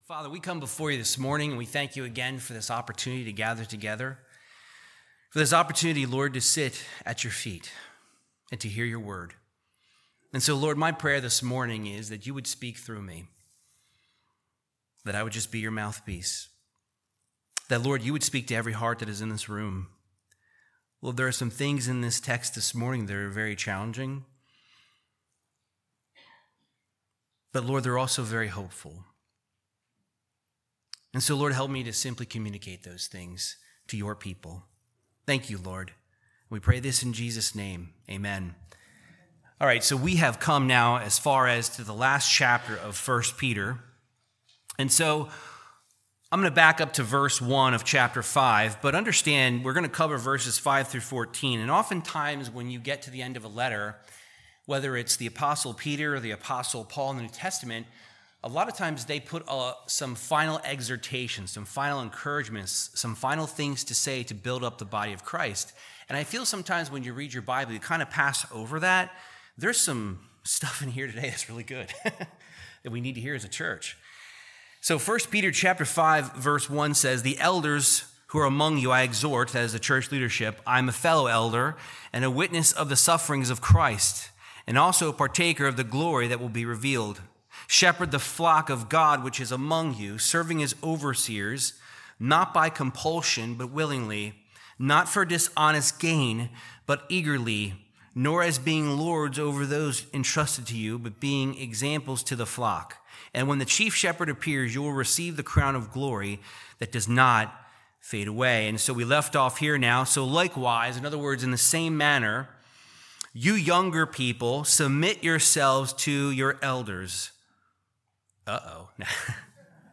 Verse by verse Bible teaching of 1 Peter 5:5-14